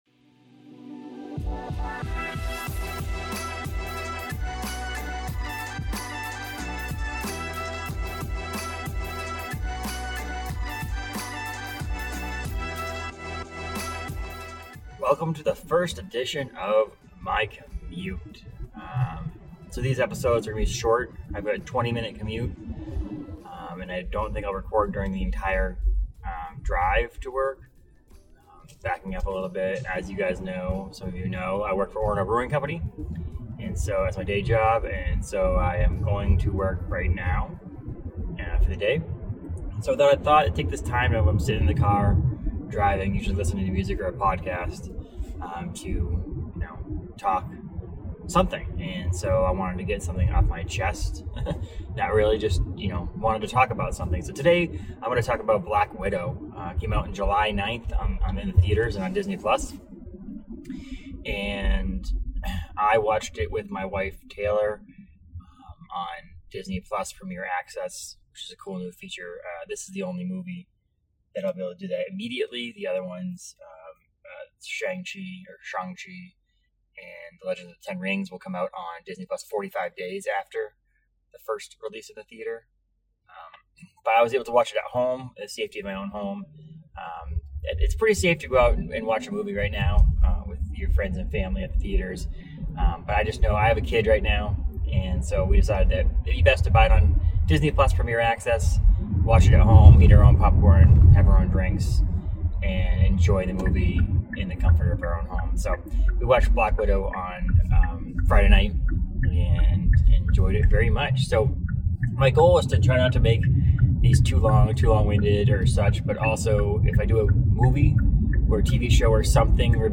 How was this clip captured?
Most of the drive consists of listen to other podcasts or music, but this time around he discusses his initial reactions to the latest MCU movie, Black Widow.